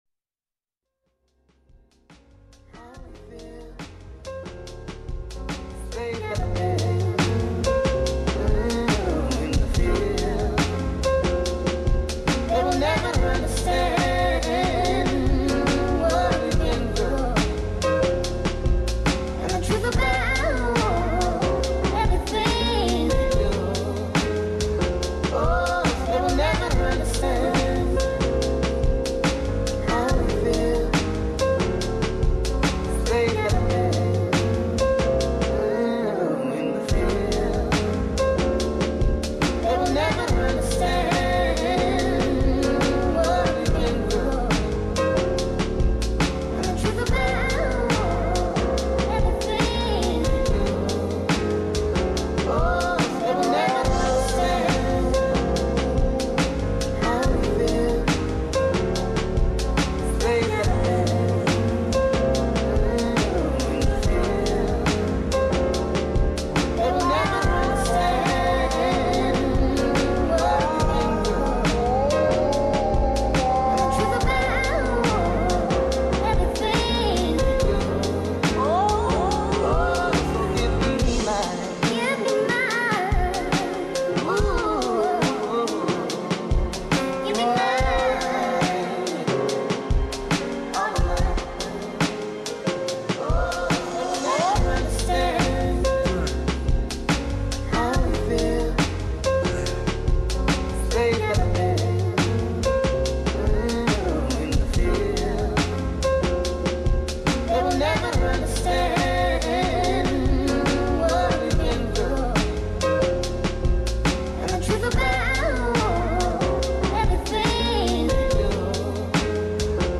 another old beat/remix
old school hip hop instrumental sample piano jazzy atmospheric dreamy chill